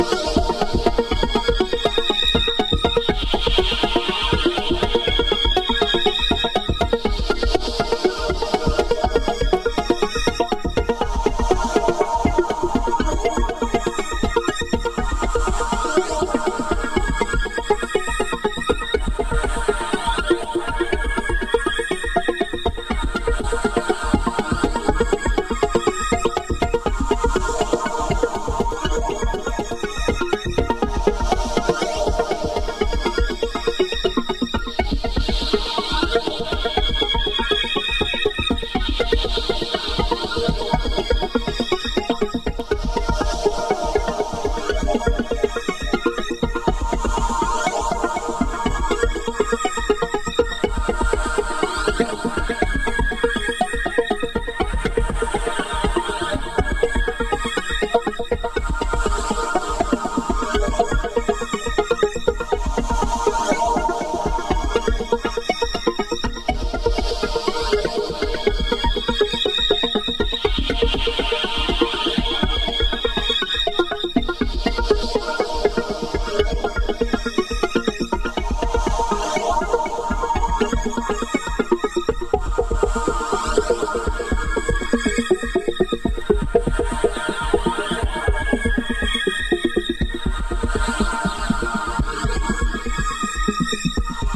独創的な凹凸、奥深いイーヴンキックの世界。
House / Techno